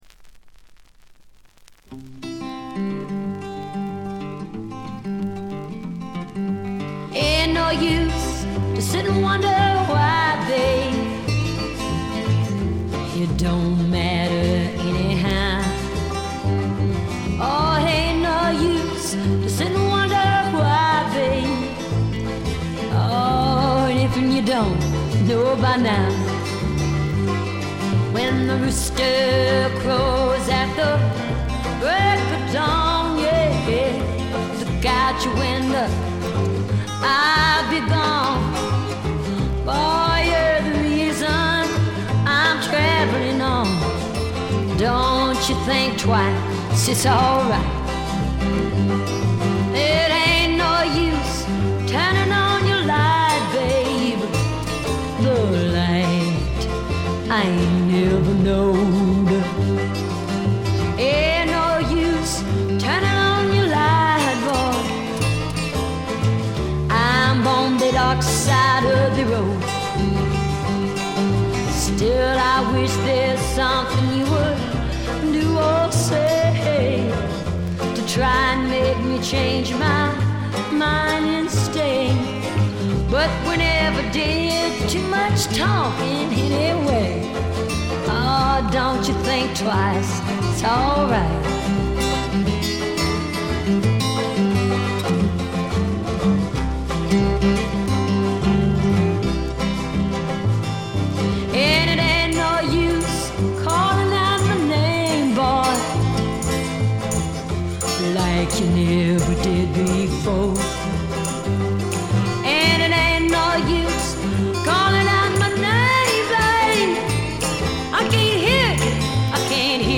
全体に細かなチリプチ、バックグラウンドノイズが出ていますが気になるのはこのB1ぐらい。
美しいフォーク・アルバムです。
最初期のモノラル盤。
試聴曲は現品からの取り込み音源です。